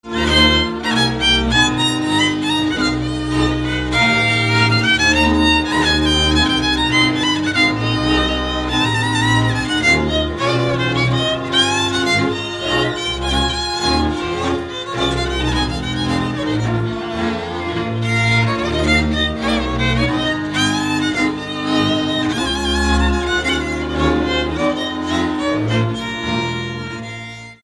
Dallampélda: Hangszeres felvétel
Erdély - Kis-Küküllő vm. - Teremiújfalu
hegedű
kontra (háromhúros)
bőgő
Műfaj: Asztali nóta
Stílus: 6. Duda-kanász mulattató stílus